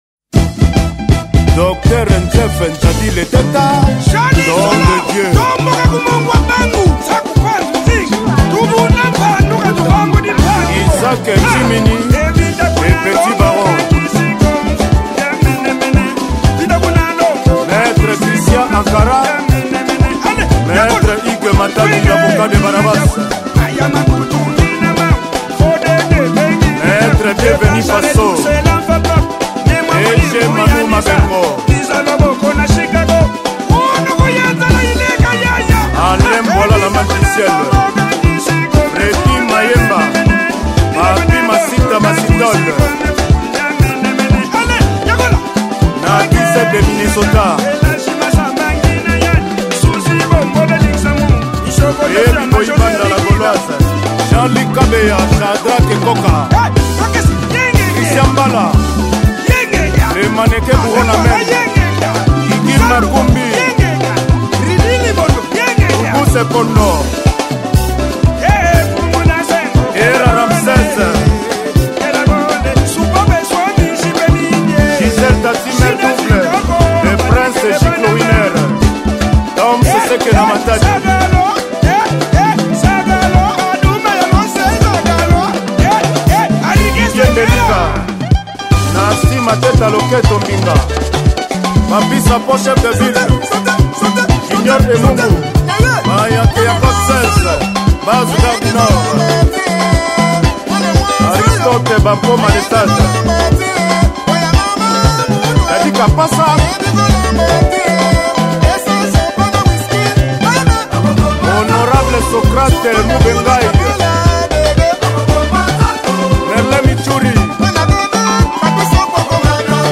Ndombolo 2025